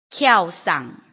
臺灣客語拼音學習網-客語聽讀拼-南四縣腔-開尾韻
拼音查詢：【南四縣腔】kiau ~請點選不同聲調拼音聽聽看!(例字漢字部分屬參考性質)